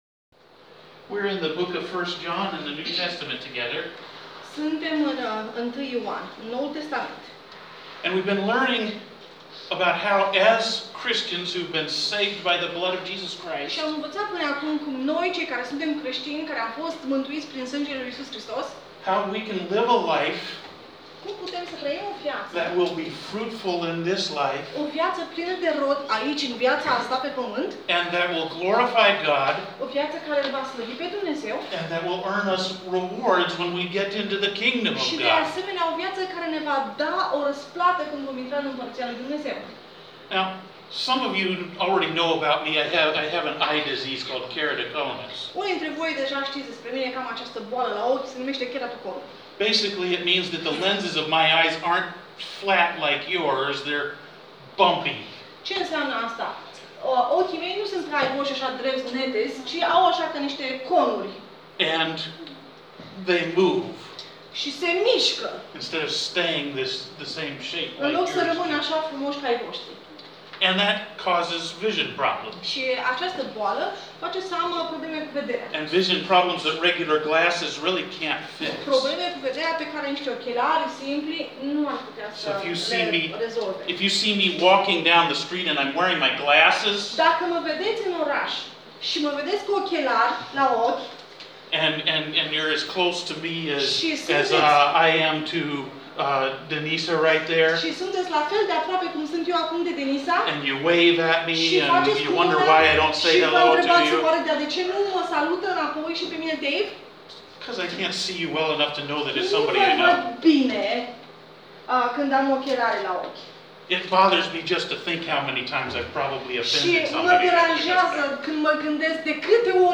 2 iunie mesaj Biblic- 1 Ioan 2:28-3:6 audio